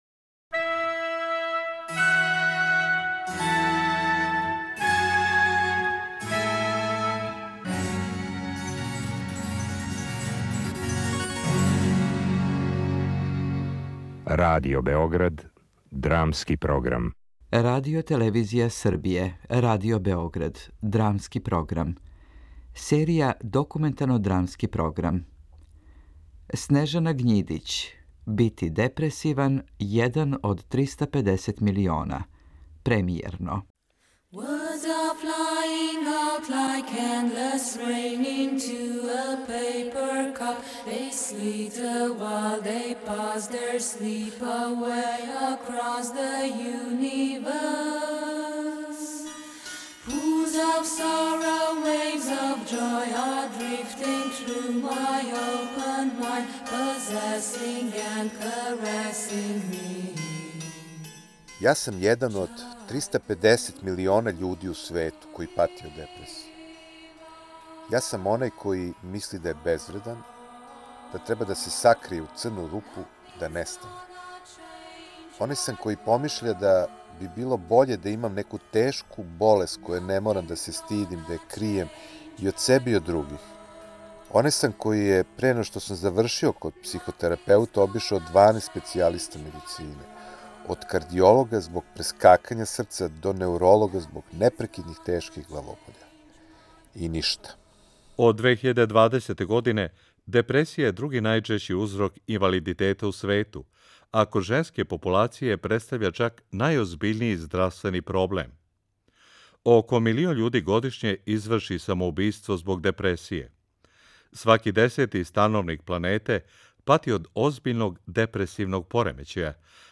Документарно-драмски програм